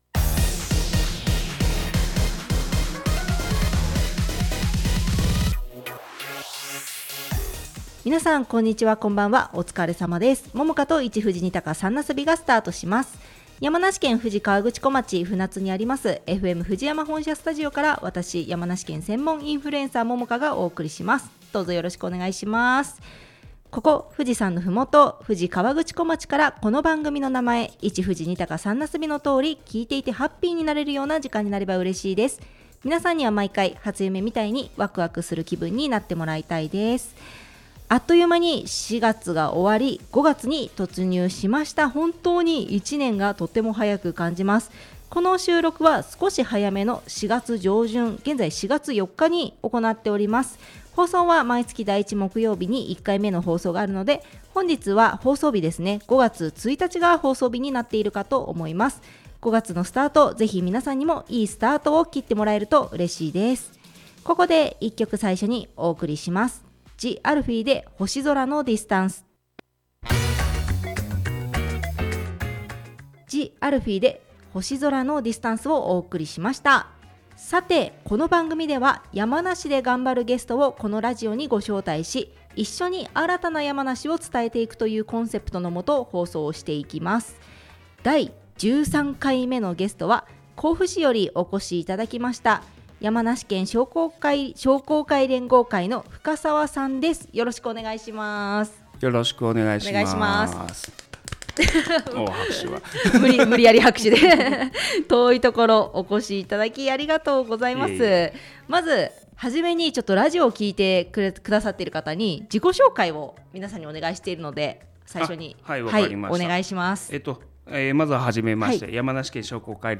（ネット配信の為楽曲はカットしています）